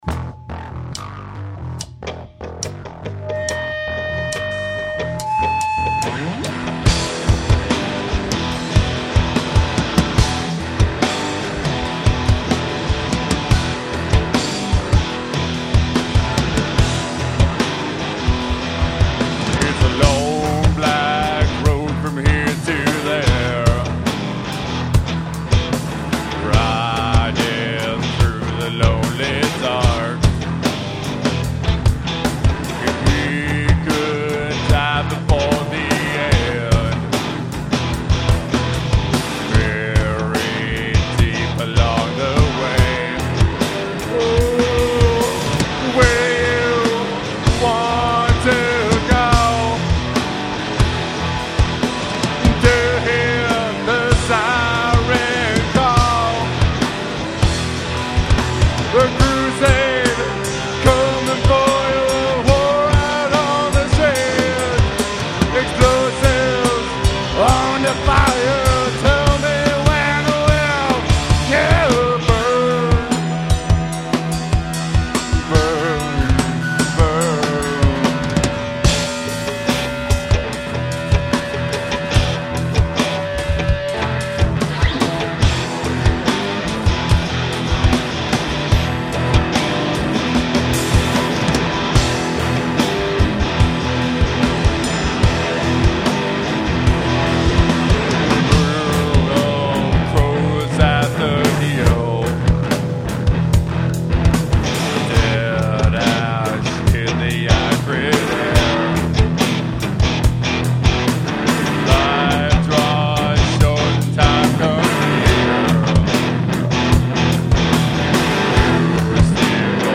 Live at The Red Sea